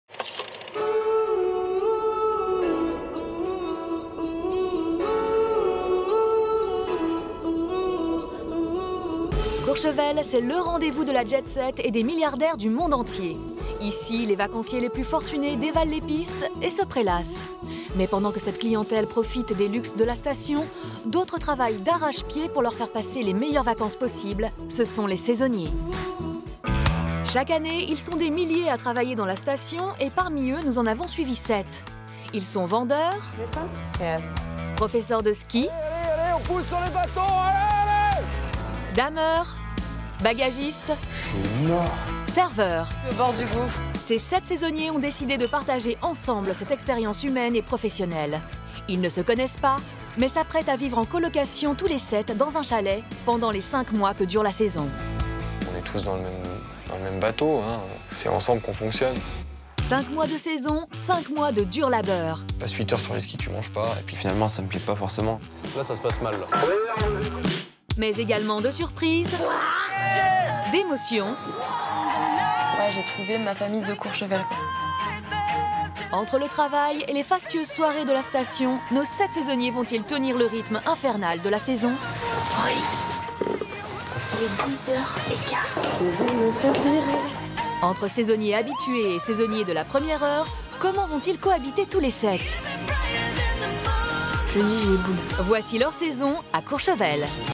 Narration série « Ma Saison à Courchevel » France 4
Voix off